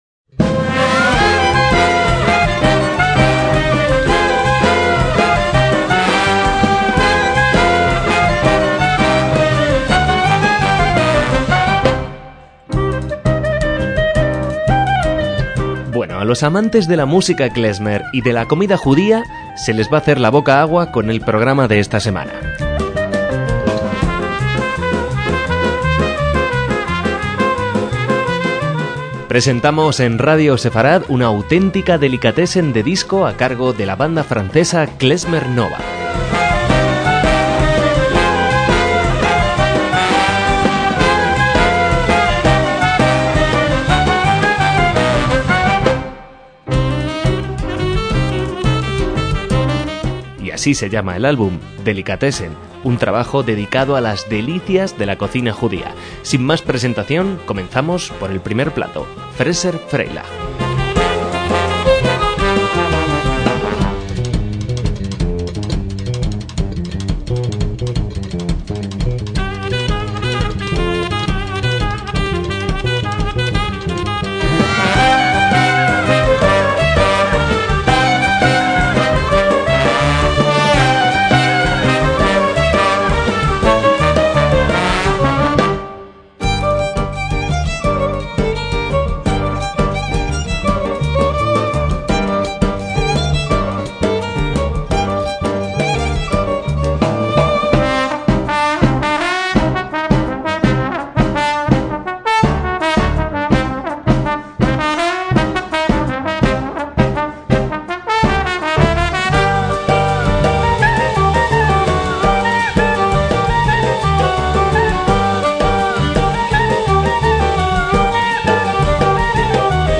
MÚSICA KLEZMER
bajo
batería
piano
trombón
trompeta
violín
clarinetes